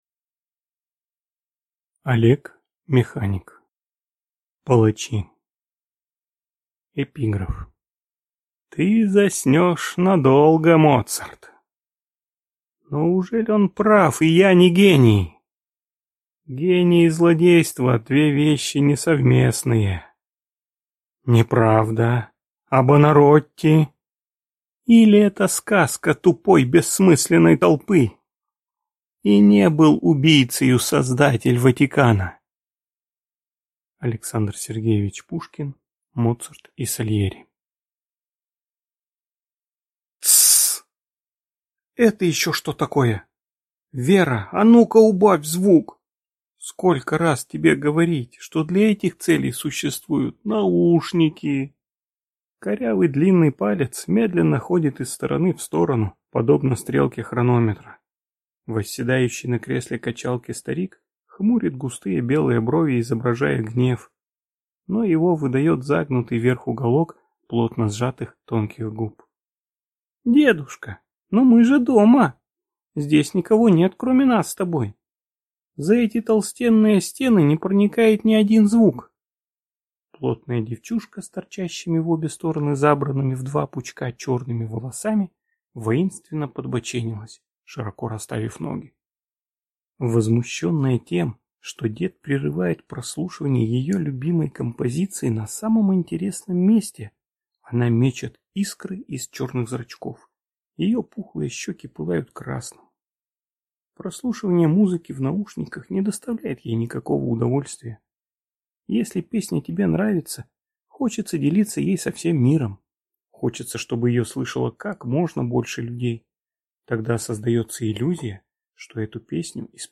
Аудиокнига Палачи | Библиотека аудиокниг